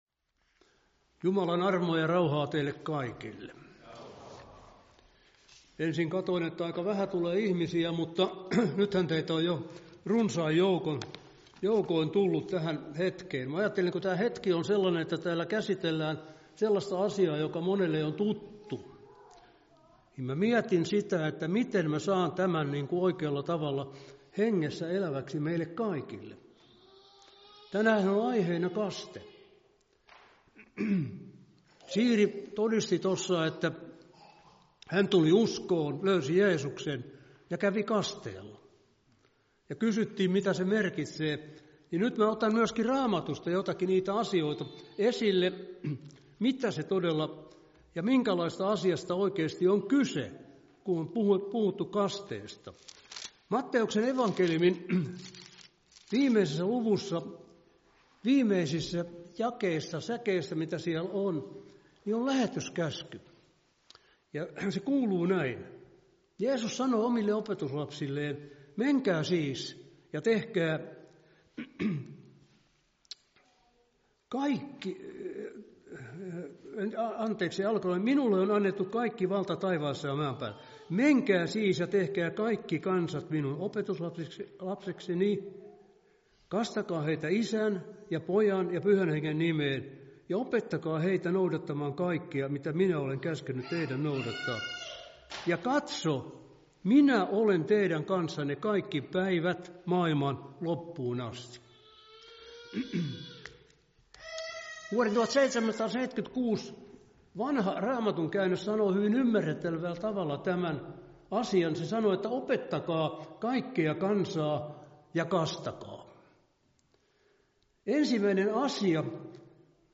Opetus kasteesta